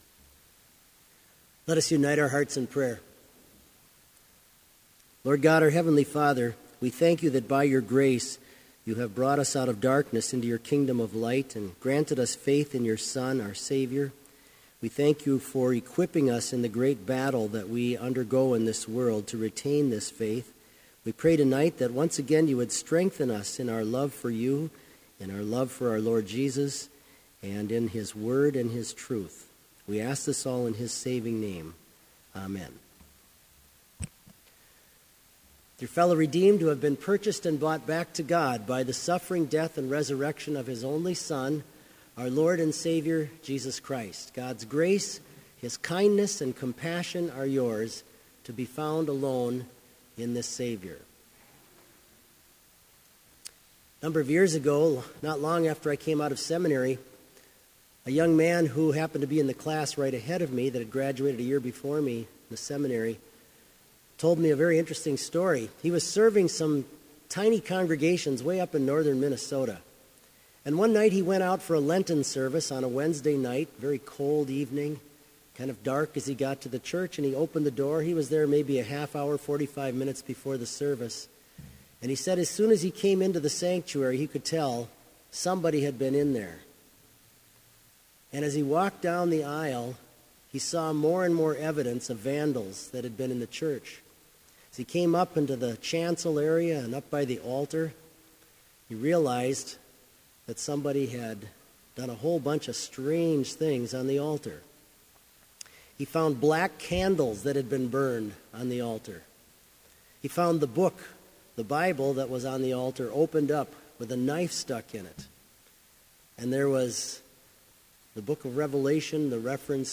Sermon audio for Evening Vespers - October 21, 2015